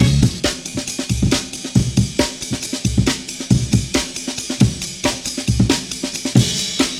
The tempo is 137 BPM.